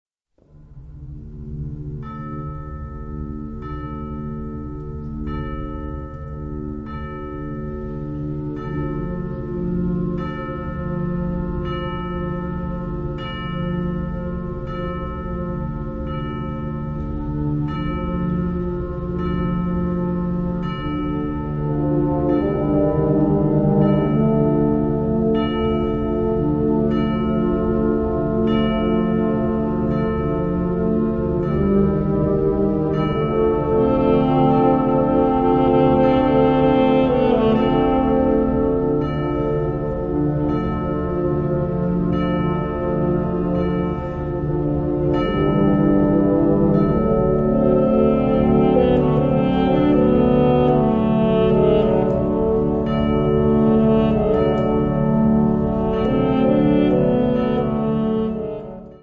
Kategorie Blasorchester/HaFaBra
Unterkategorie Zeitgenössische Originalmusik (20./21.Jhdt)
Besetzung Ha (Blasorchester)